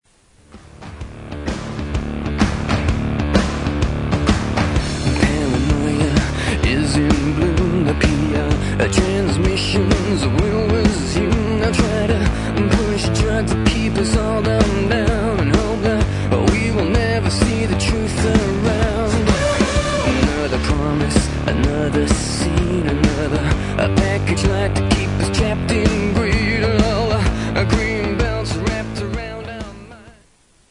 • Pop Ringtones